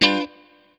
CHORD 7   AG.wav